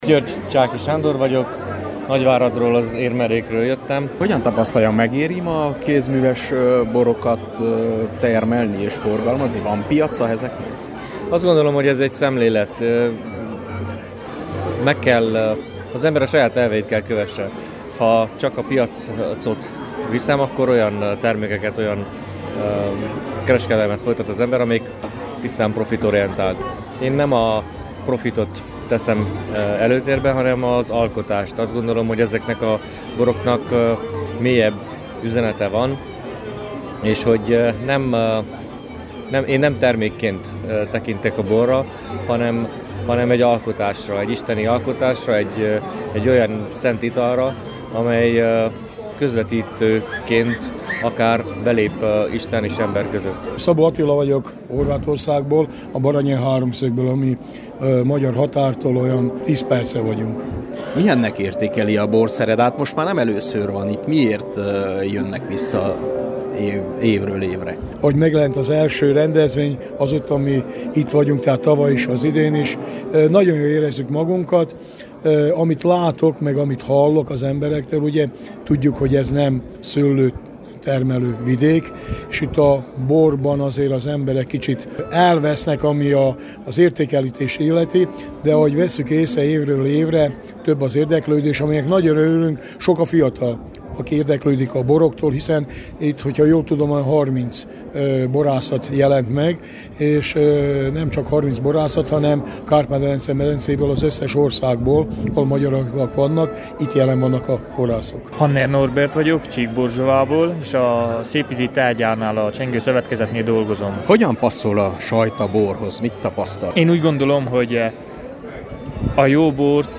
a Borszeredán készült: